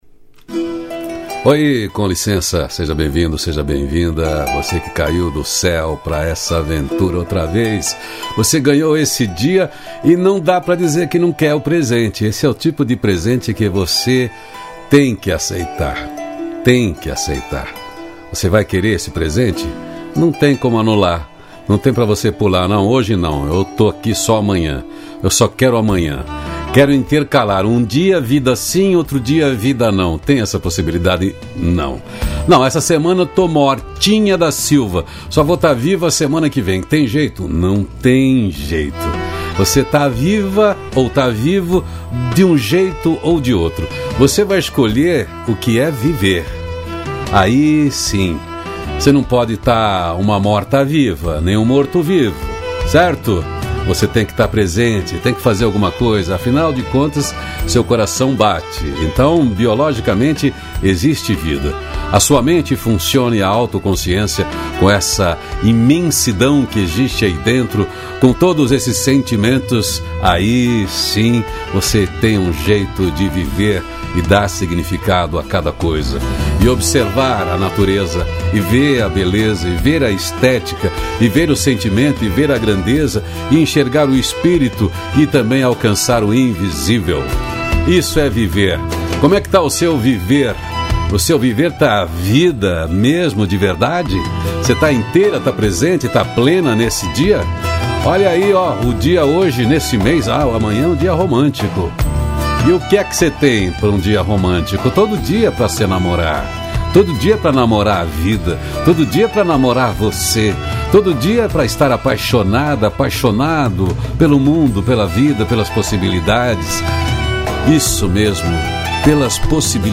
CRÉDITOS: Produção e Edição: Onion Mídia Trilha Sonora: “Between the Shadows” Loreena Mckennitt